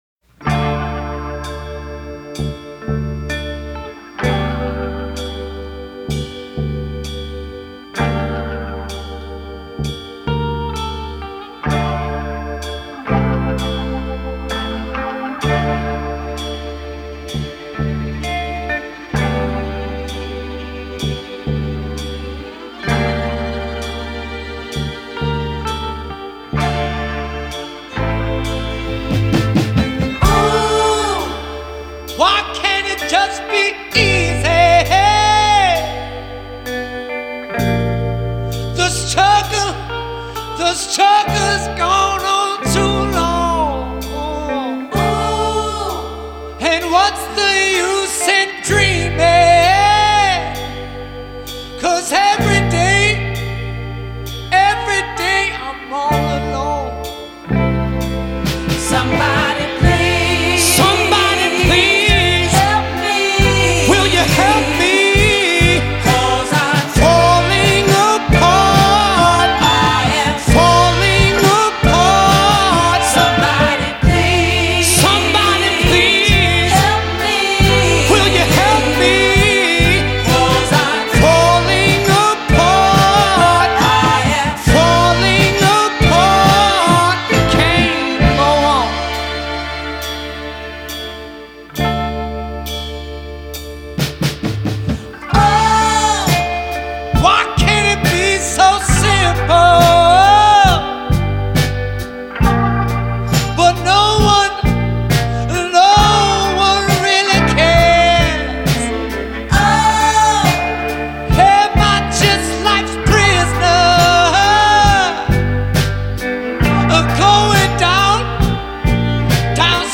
Style: Funk, Soul, Psychedelic